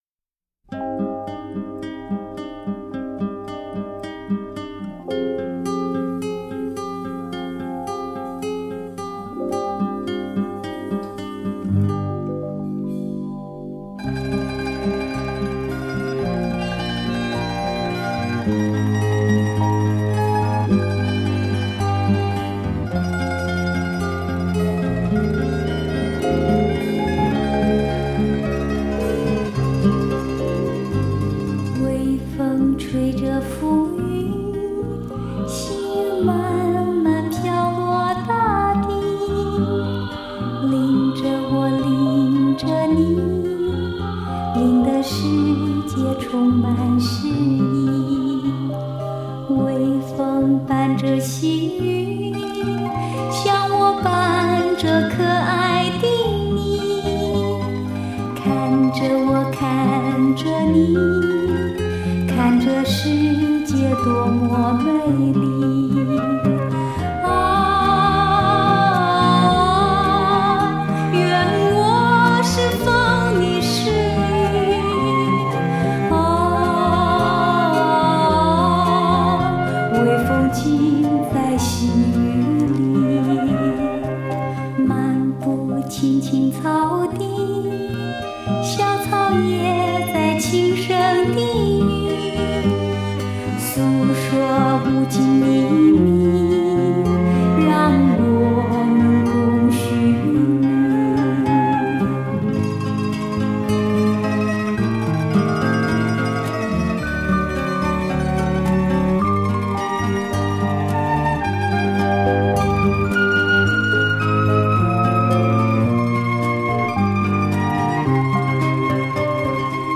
专辑仍以清新的民歌调性作为整体主轴